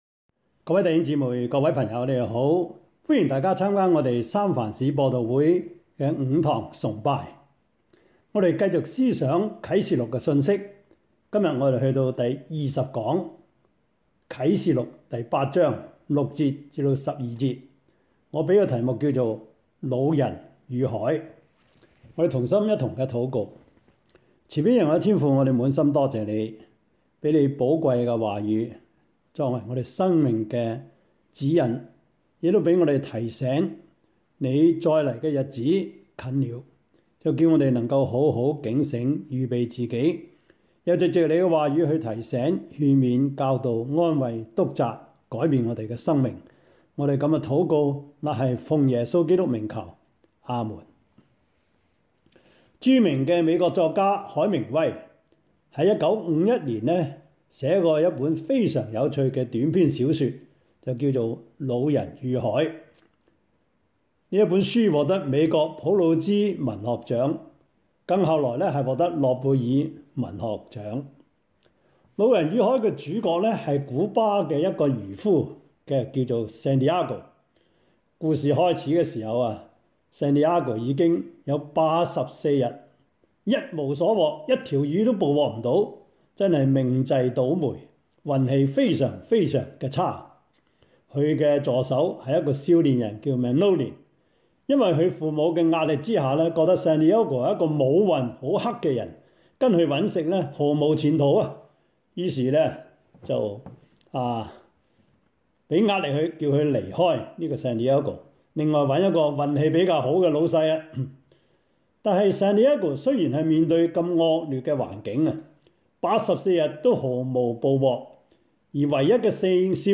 Service Type: 主日崇拜
Topics: 主日證道 « 神的國如何成長?